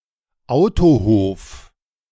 De-Autohof.ogg.mp3